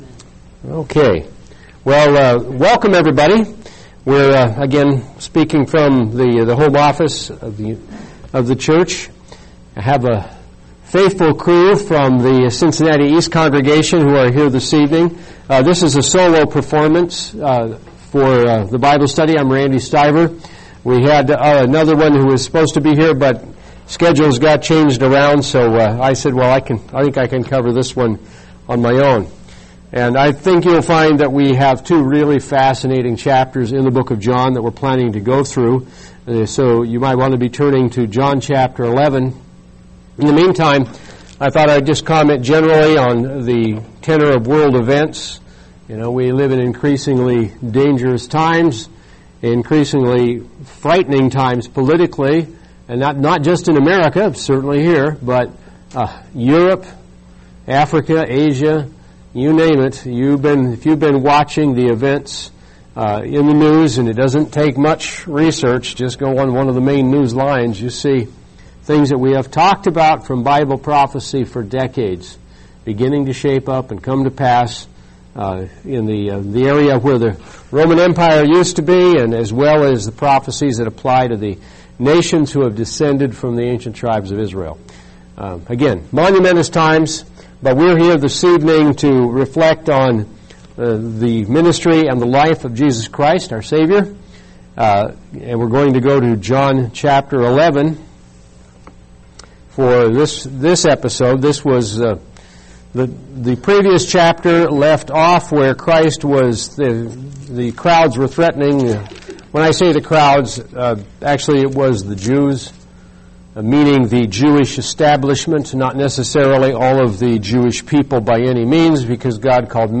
Bible Study
Given in Cincinnati East, OH